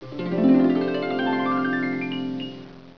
Scales
Scales.wav